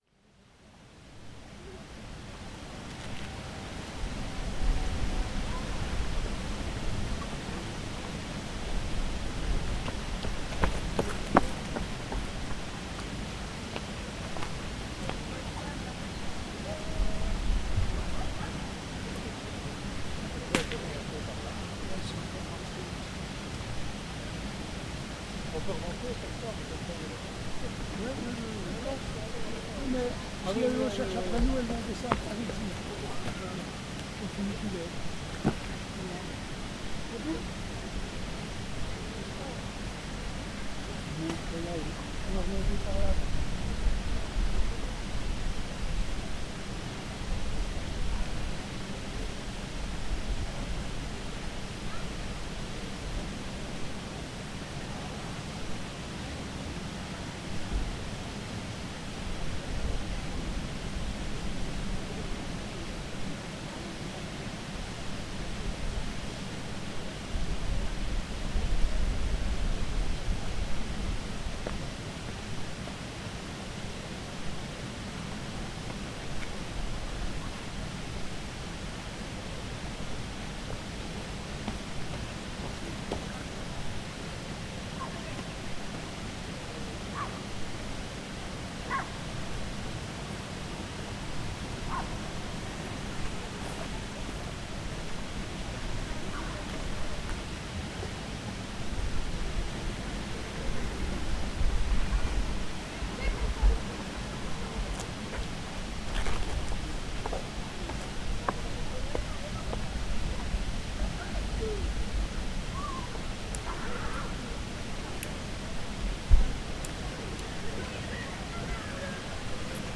Gravação do som de uma levada no rio Pavia e dos passos de quem percorre um passeio. Gravado com Zoom H4.
Tipo de Prática: Paisagem Sonora Rural